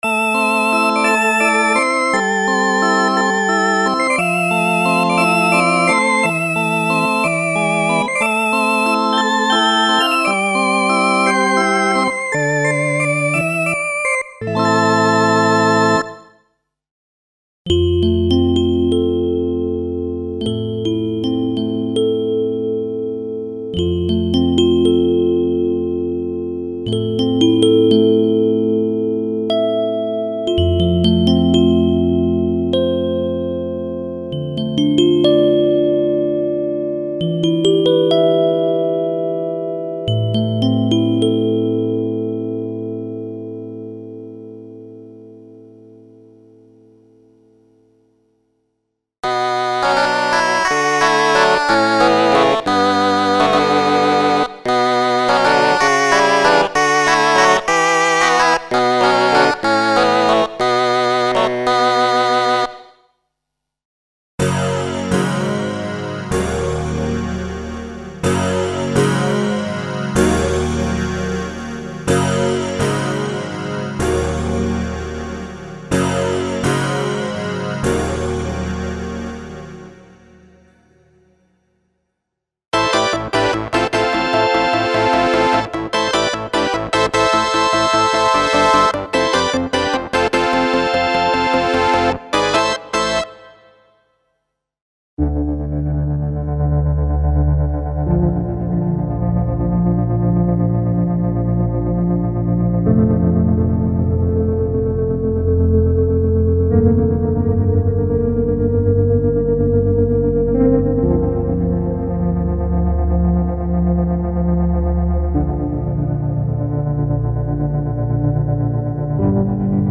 Original collection of piano and organ sound programs in experimental and 70s retro feeling, created to take advantage of the special controller settings for internal DSP modulations (e.g. filter, pitch, distortion, shaper, etc).
Info: All original K:Works sound programs use internal Kurzweil K2600 ROM samples exclusively, there are no external samples used.